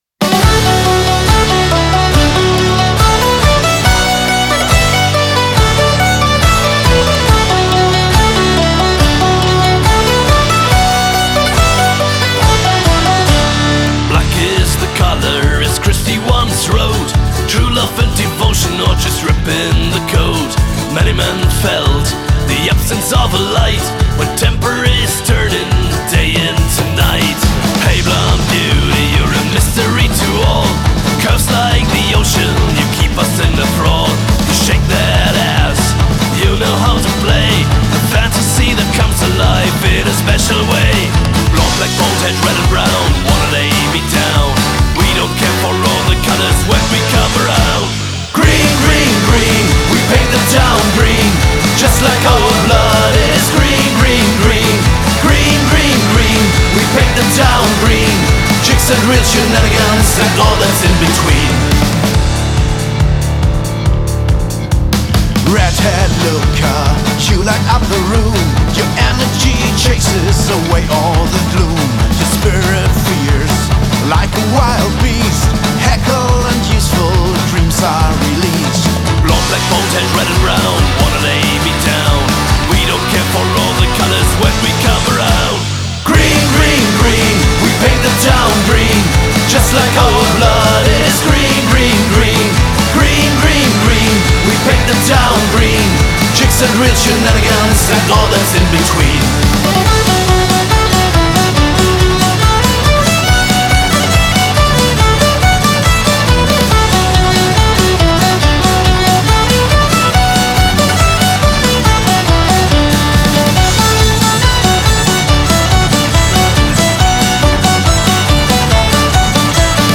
Genre: Rock-Folk